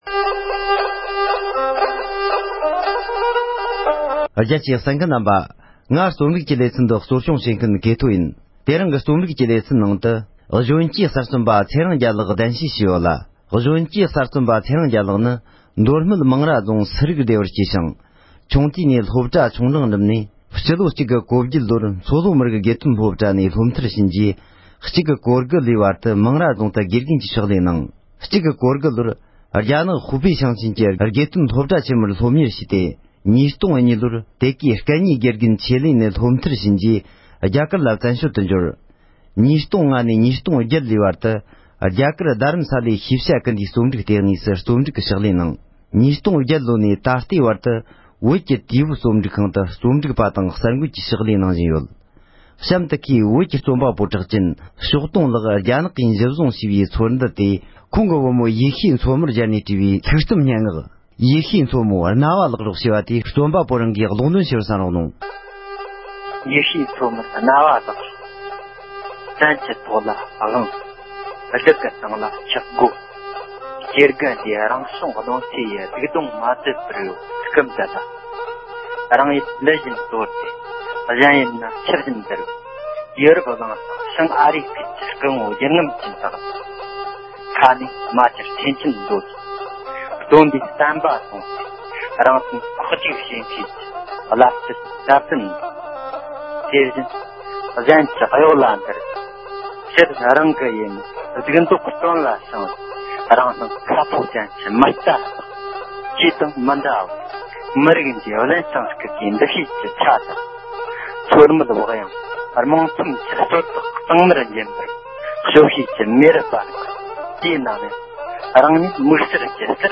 ལྷུག་རྩོམ་ཞིག་སྒྲོག་འདོན་ཞུས་པར་གསན་རོགས༎